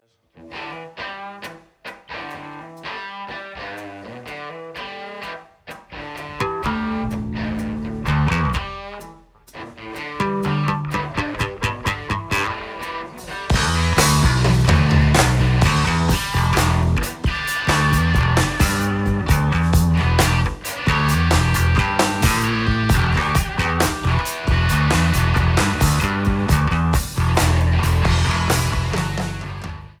Blues
Jamband
Rock